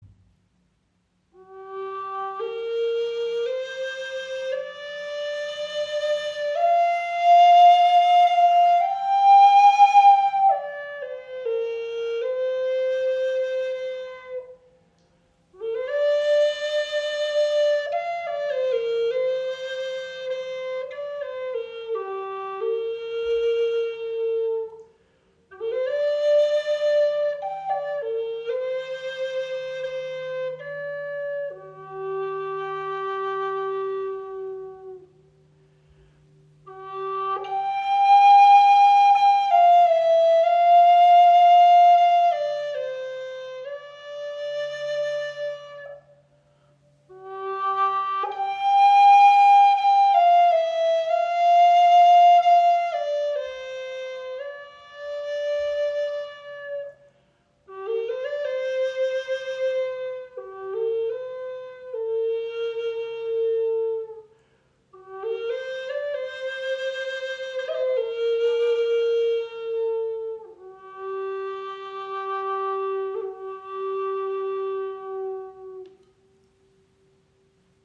Die Flöte liegt angenehm in der Hand, spricht leicht an und entfaltet einen klaren, warmen und zugleich robusten Ton.
Lightning Bear Gebetsflöte in G – 440Hz
Der Flötenkörper wurde aus edlem Padauk-Holz gefertigt, einem Holz mit warmer, rötlicher Färbung und einem vollen, erdverbundenen Klang.